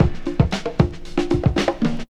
09DR.BREAK.wav